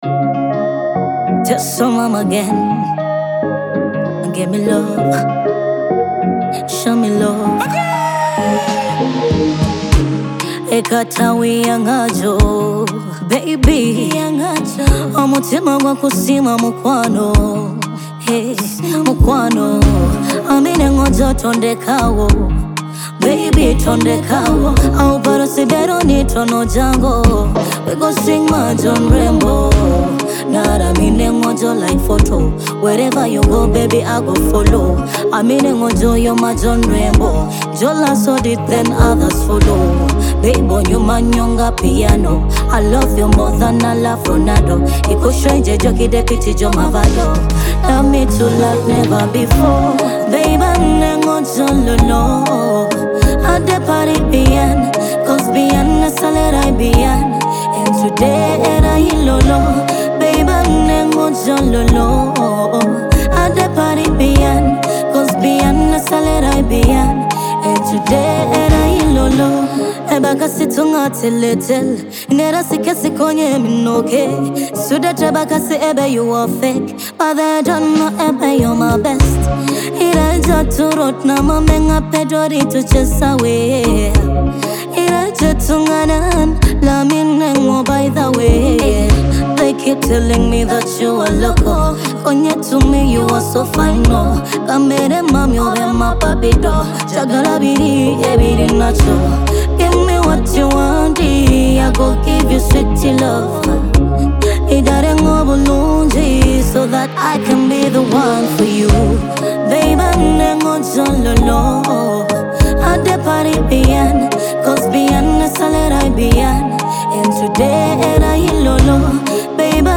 emotive vocals
modern Teso sounds with timeless love themes